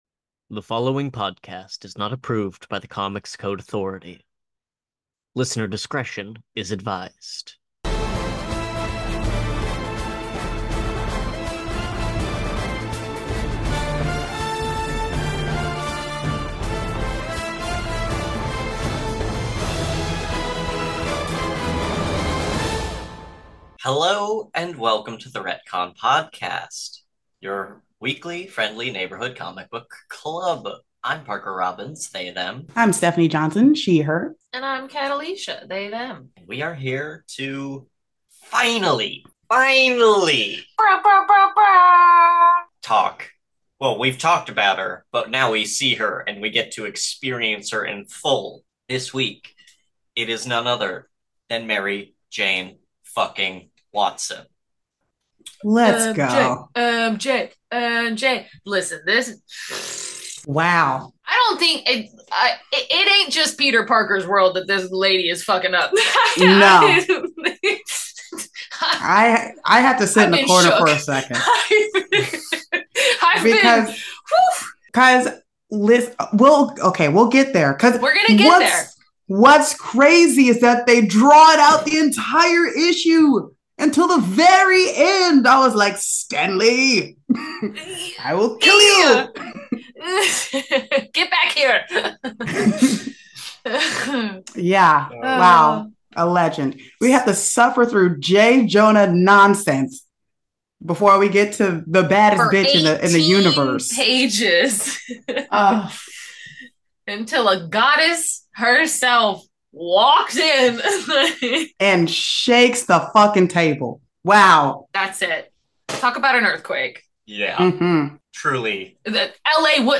The Retcon Podcast is recorded in Los Angeles